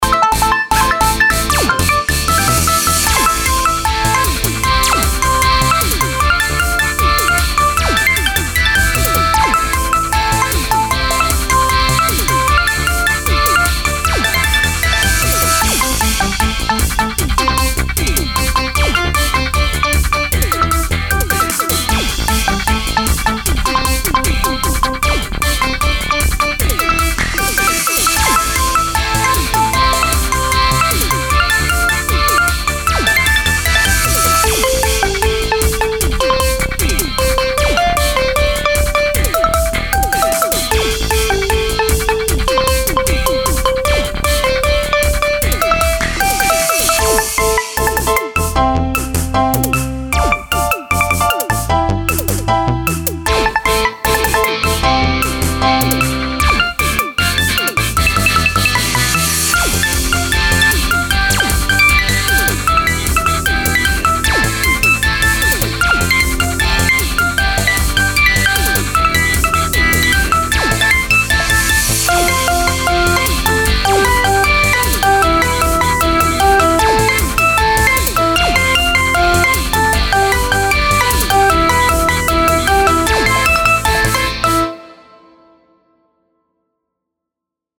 BGM
アップテンポショート